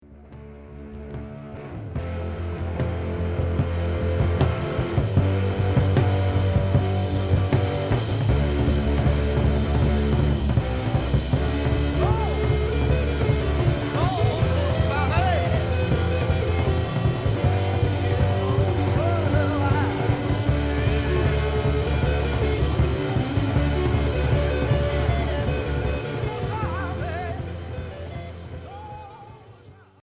Progressif Unique 45t retour à l'accueil